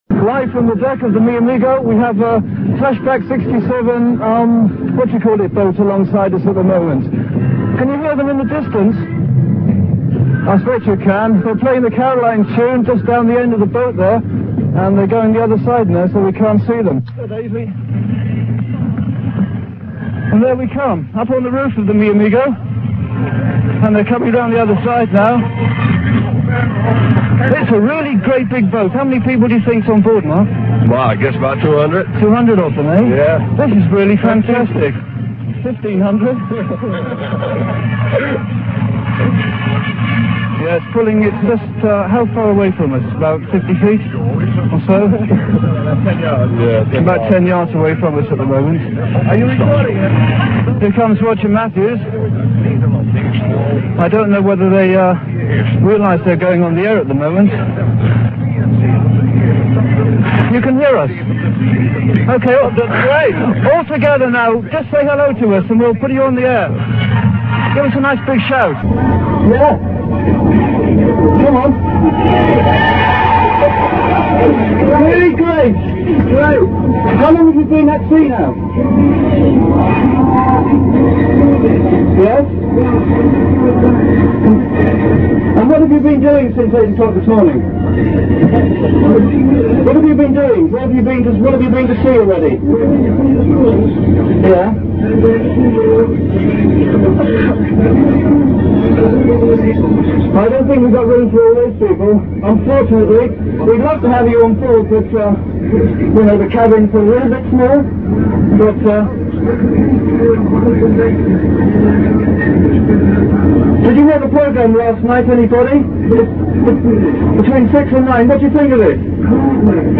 click to hear audio A mix of two tapes - an off-air recording and one made on the Flashback '67 boat.
The other, muffled, voice